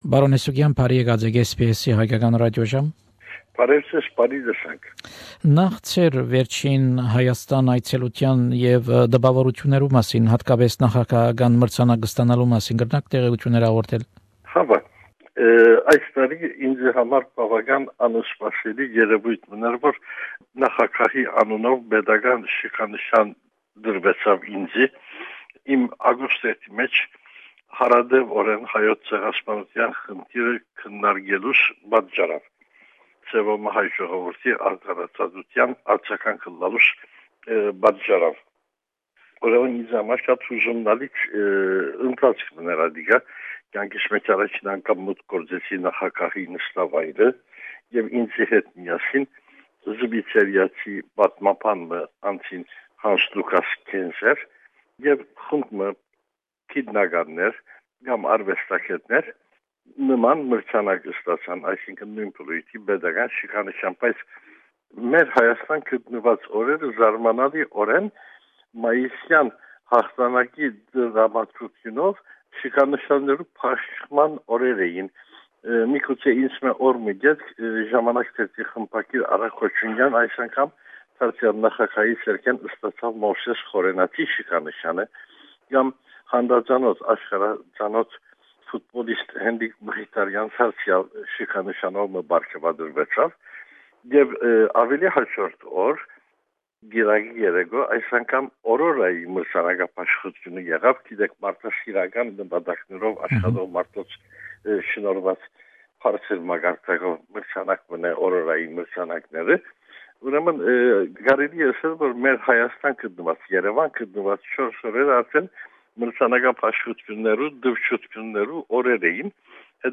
This is the full interview.